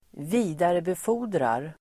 Uttal: [²v'i:darebefo:r_drar]
vidarebefordrar.mp3